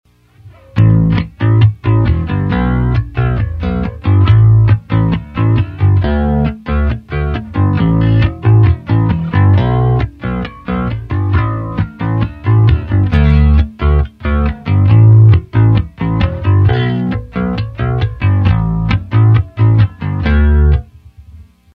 With the bass pod I can get a wide range of clean or dirty sounds and various effects and model different amps and speaker cabinets.
Sound bytes for each effect isolated using a 5 string jazz bass.
Bass Pod 2    Bass Pod 3
bass-pod-2.mp3